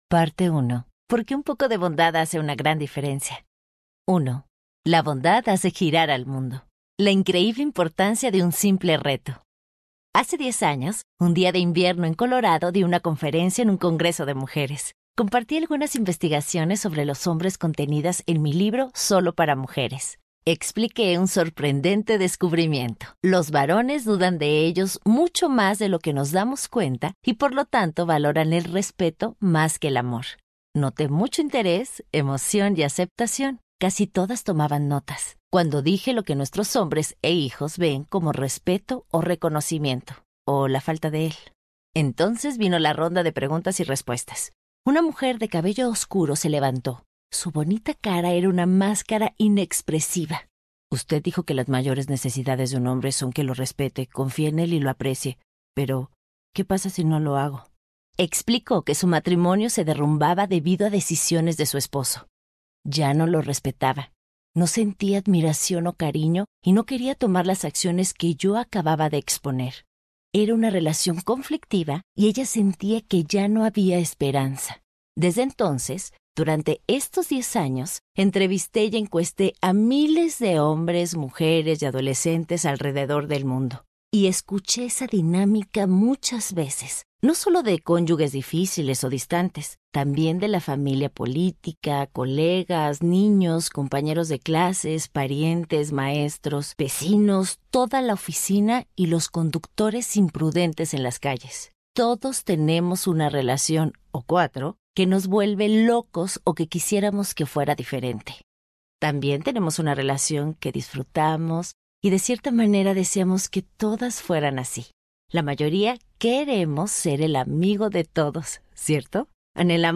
El poder de la bondad Audiobook
Narrator